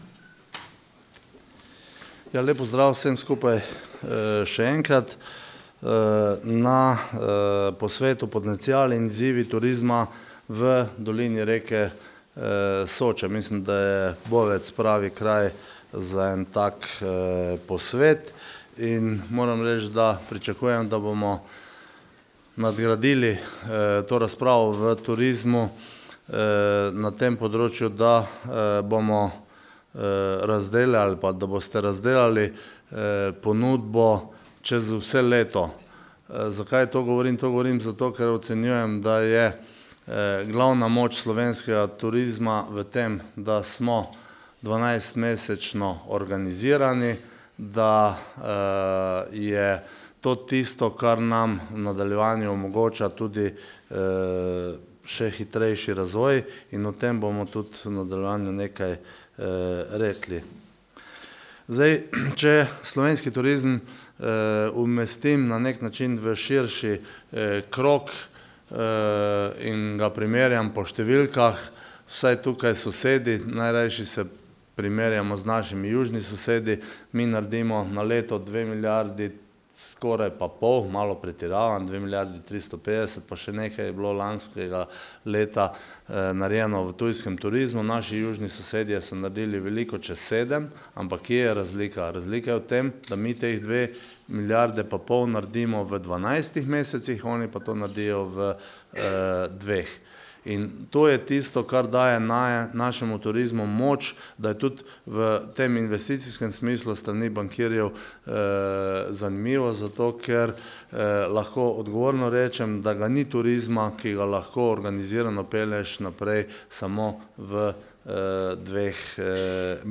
Predstavitev minister Počivalšek